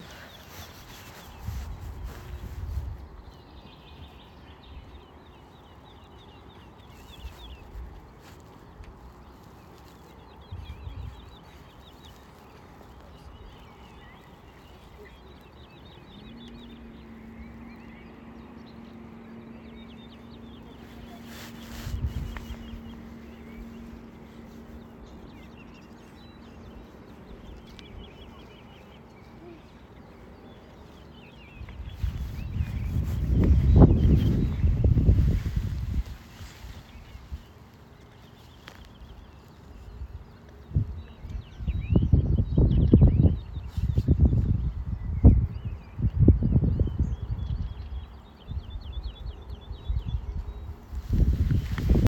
Birds -> Larks ->
Woodlark, Lullula arborea
StatusVoice, calls heard